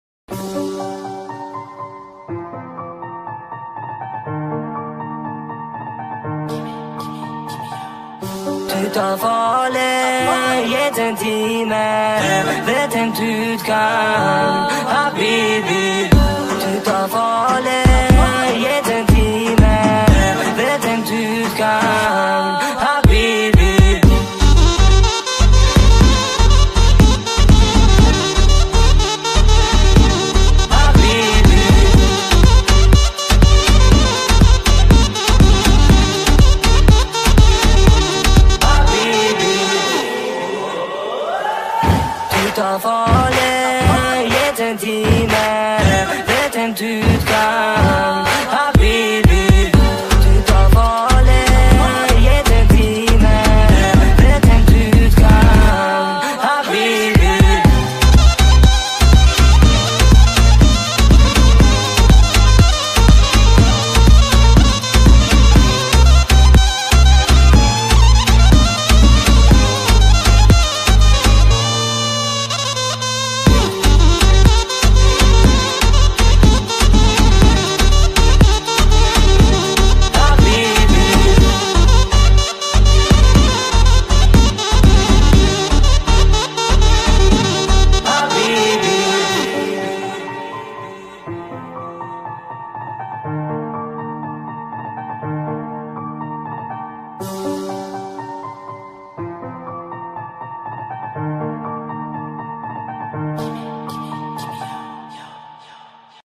نسخه Sped Up